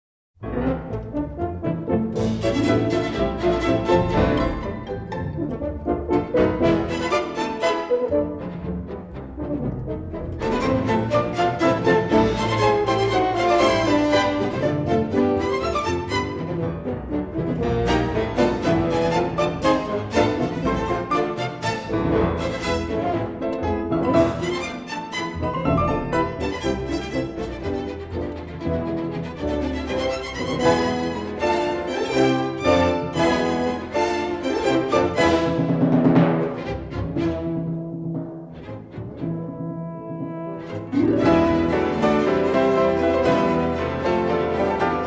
remastered in stereo from the original three-track masters